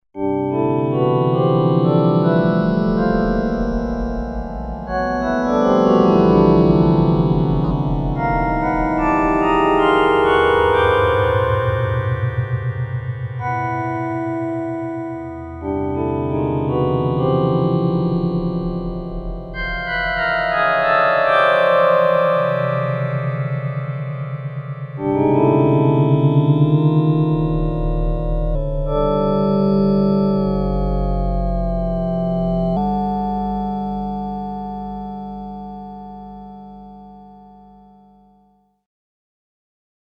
Class: Synthesizer